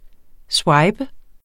Udtale [ ˈswɑjbə ]